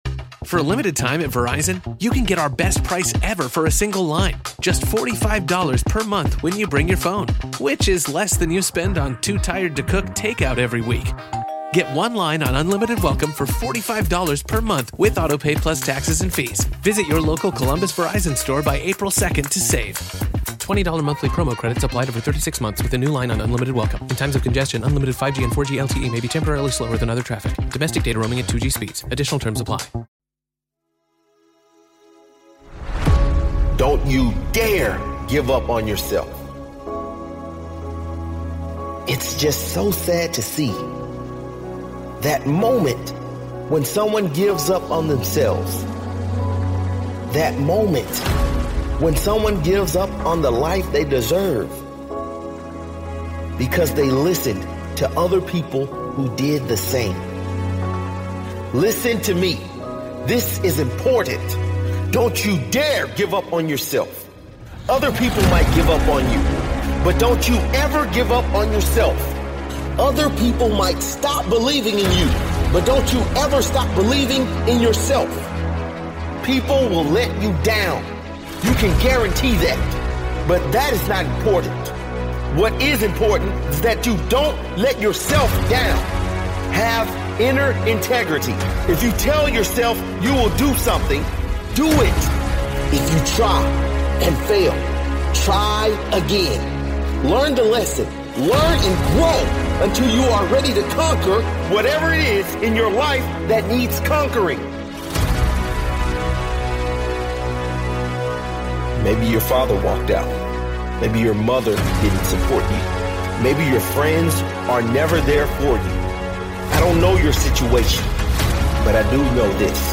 Don’t you DARE GIVE UP on YOURSELF - Powerful Motivational Speech